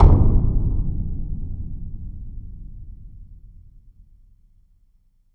tympani.wav